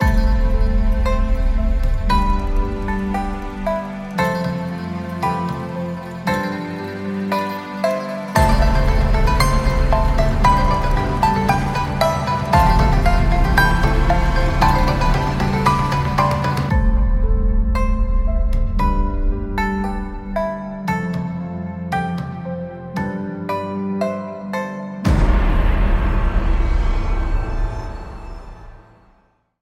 在我们的第一个 Strings of the Orient 虚拟乐器库中，我们精心捕捉了具有 2000 年历史的中国琵琶（琵琶）的每一个音符。
由屡获殊荣的琵琶演奏家演奏。
Solo Pipa 是在新加坡城邦录制的，其作品受到环球音乐集团、华纳音乐集团、索尼音乐娱乐、漫威影业和 Facebook 等行业巨头的信赖。
您在这个库中体验到的每一个音符都是用行业领先的专业人士使用的最先进的设备录制和制作的——因为我们知道，您不会满足于最高质量的声音。